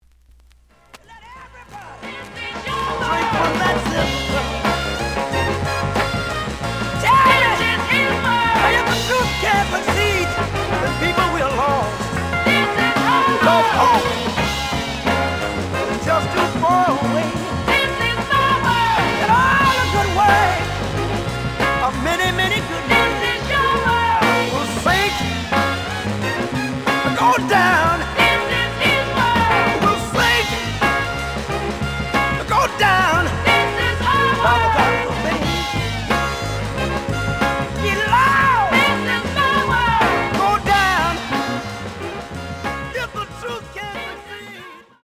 試聴は実際のレコードから録音しています。
●Genre: Soul, 70's Soul